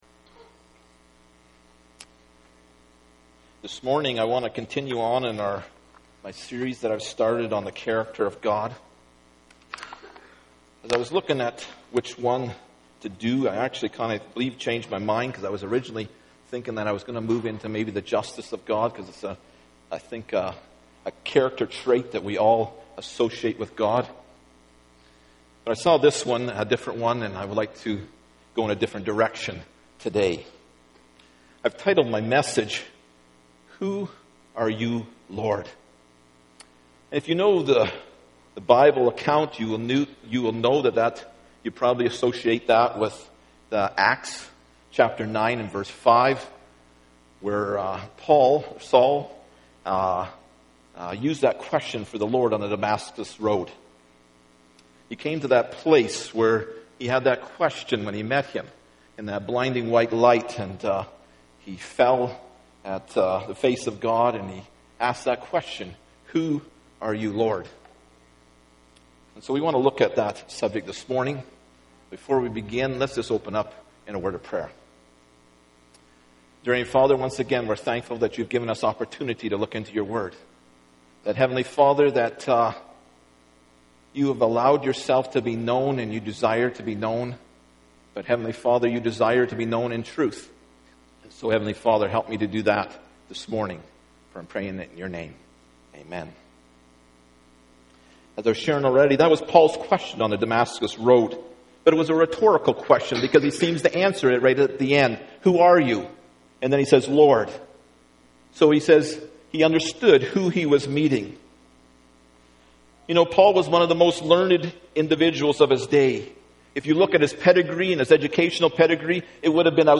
Acts 9:5 Service Type: Sunday Morning Bible Text